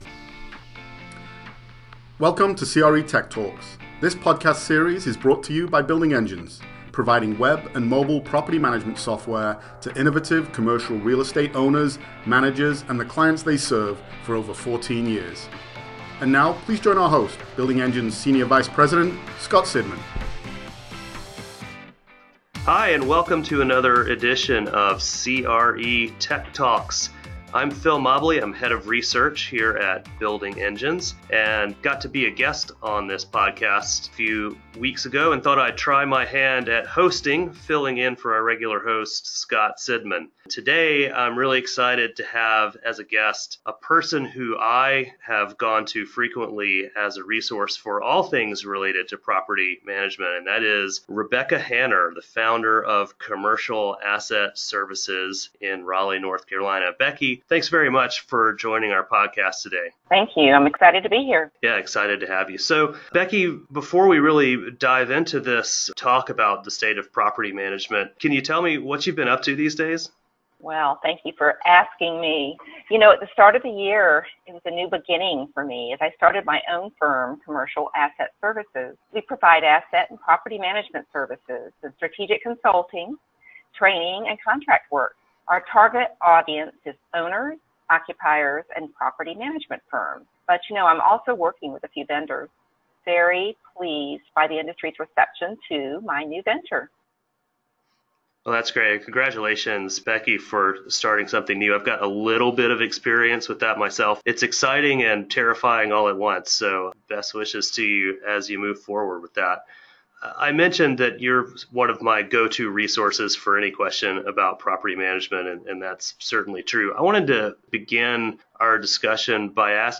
A conversation on how the role of the property manager is evolving alongside tenant expectations and technology.